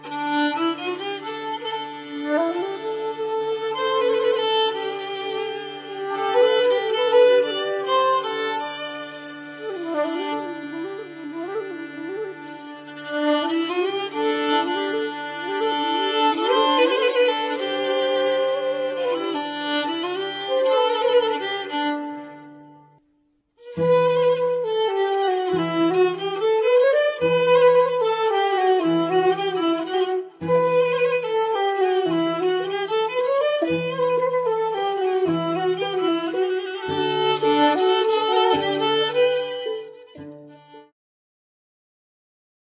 basy bass, śpiew voice